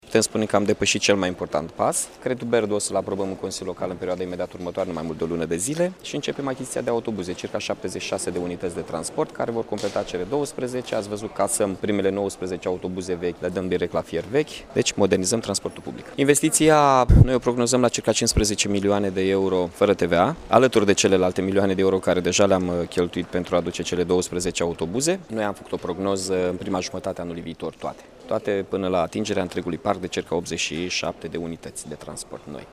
Chirica a mai anunţat că cele 19 autobuze cu motoare non-euro vor fi casate: